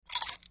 Bite Into Many Potato Chips, X7